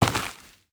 Dirt footsteps 15.wav